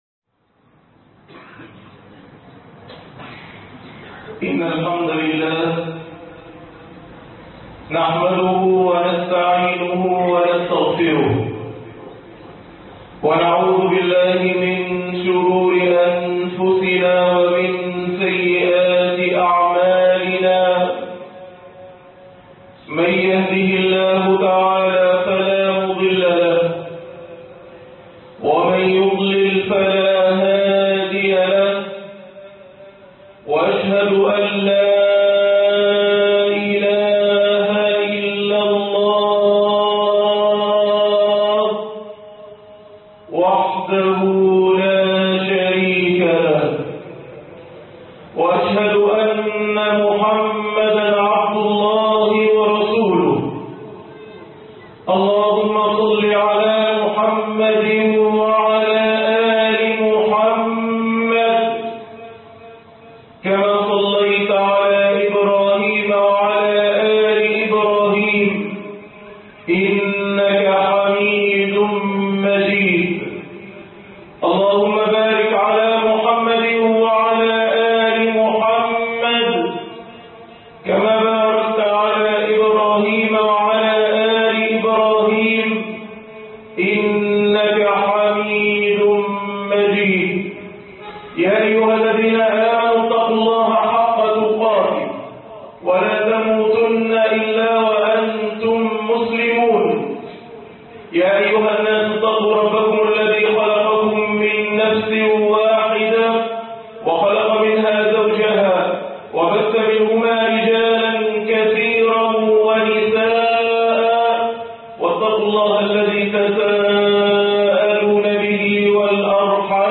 أنواع القلوب-علامة القلب الذي مع الله ( 1/11/2013)خطب الجمعة